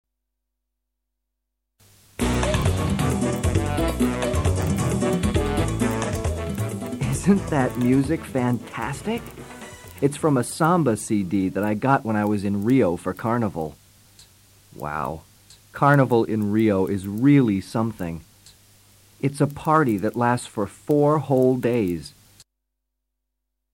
Listen to an American tourist explaining about famous Carnival